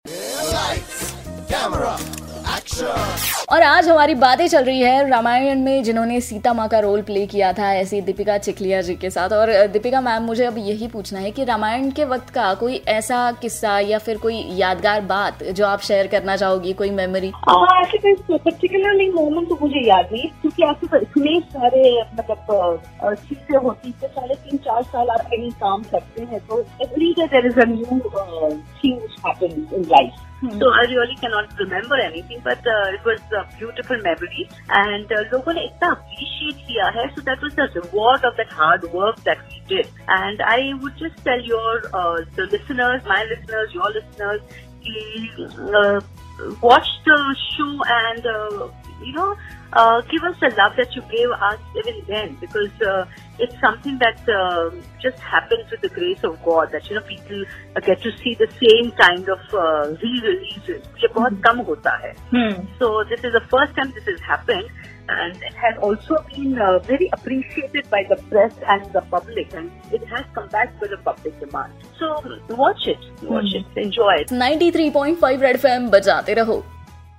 In this interview Dipika Chikhlia shared her memories of Ramayan.